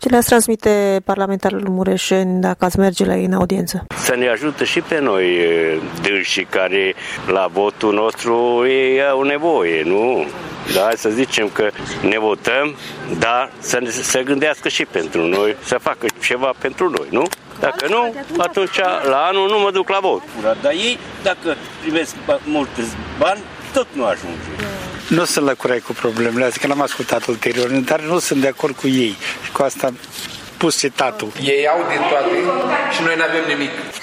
Tîrgumureşenii încă nu au încredere în parlamentarii pe care i-au votat şi din cauza imaginii negative pe care o au în general parlamentarii români: